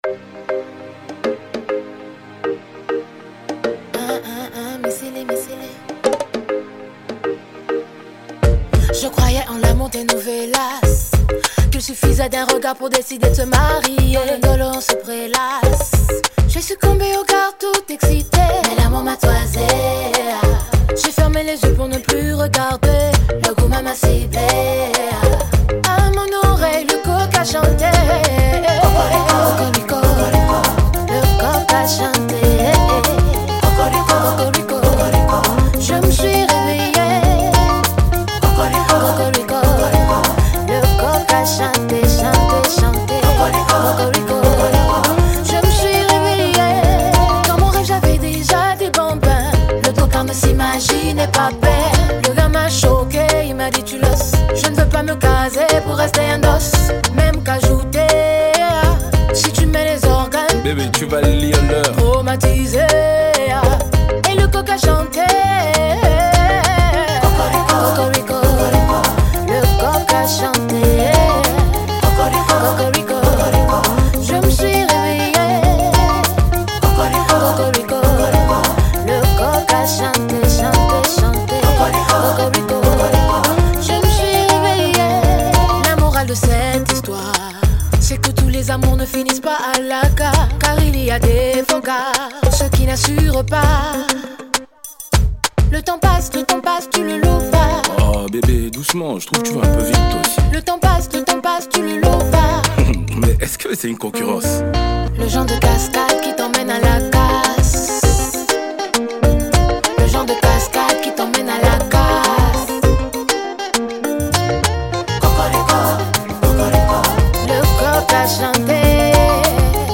Genre : Zouk love